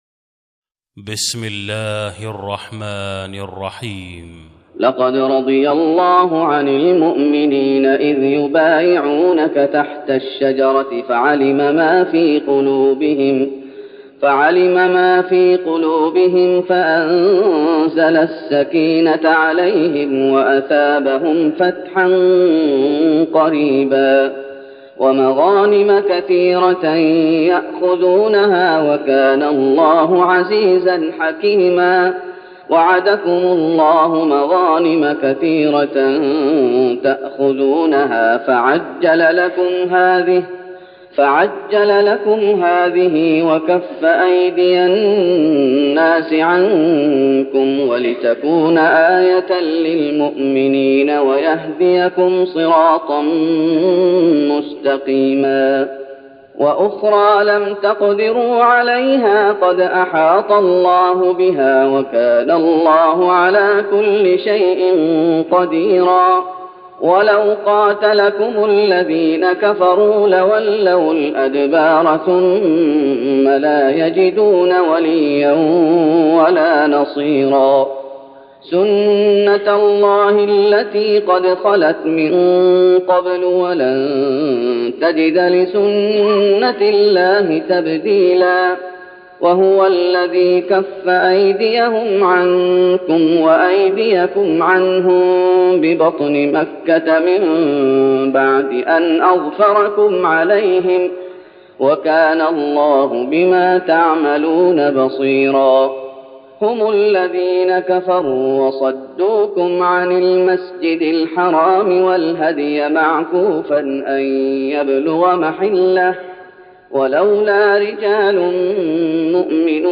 تراويح رمضان 1412هـ من سور الفتح (18-29) Taraweeh Ramadan 1412H from Surah Al-Fath > تراويح الشيخ محمد أيوب بالنبوي 1412 🕌 > التراويح - تلاوات الحرمين